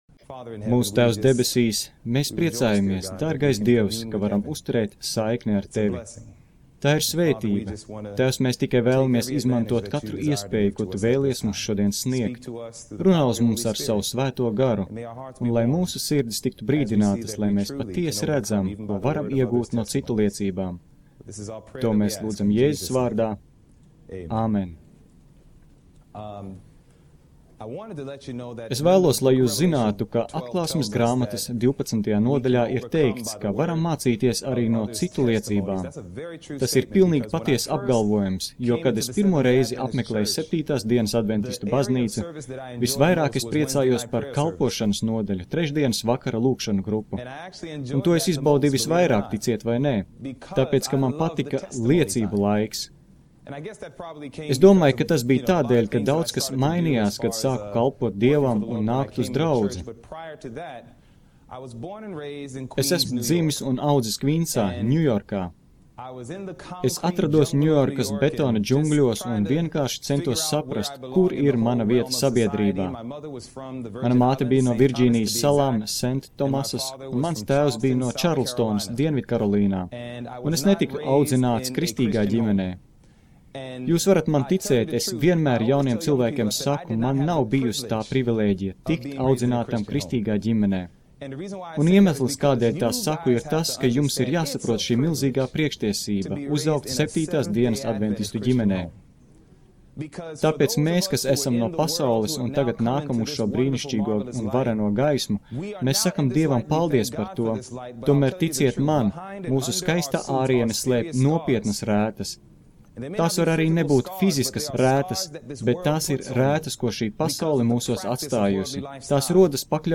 Liecība - Paklausies